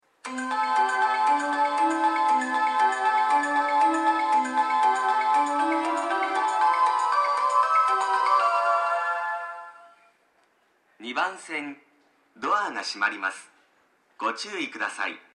この駅の発車メロディーは両番線ともにテイチク製の「秋桜」が使用されています。
またスピーカーは小ボスが使用されており音質はとてもいいと思います。
発車メロディーフルコーラスです。